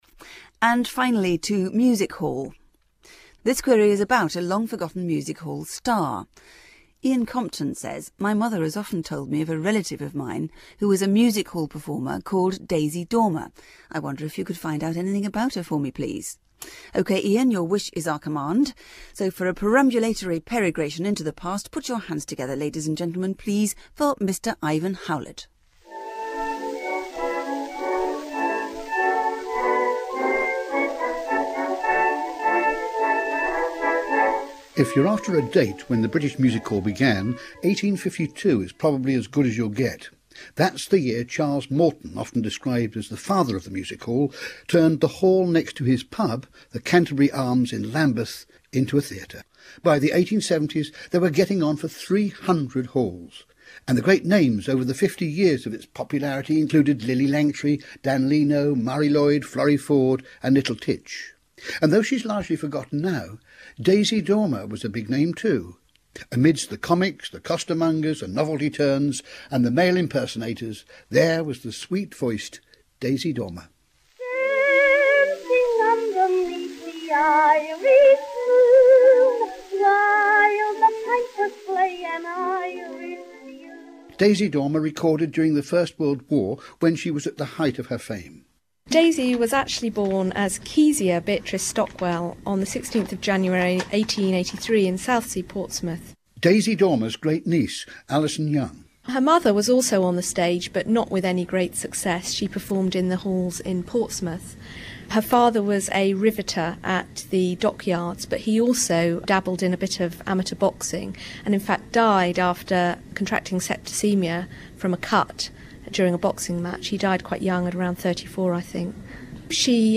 Daisy sings!
Here is a part broadcast about Daisy.